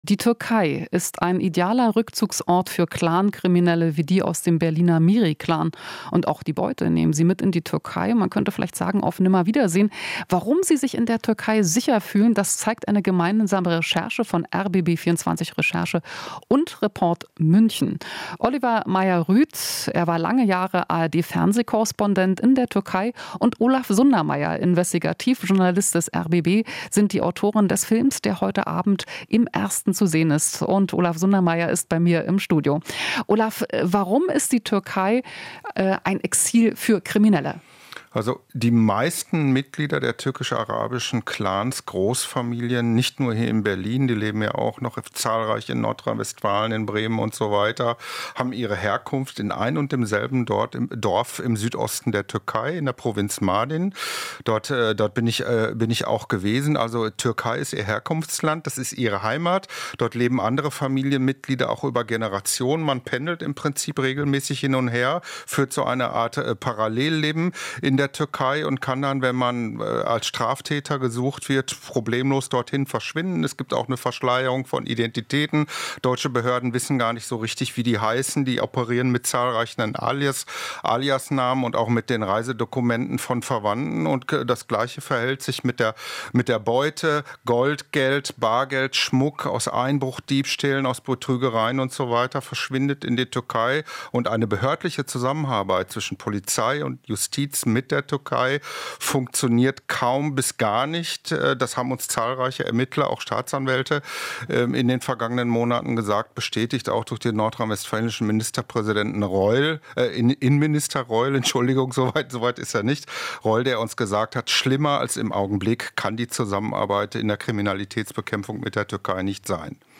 Interview - Clanland: Die Türkei als Rückzugsort für kriminelle Clans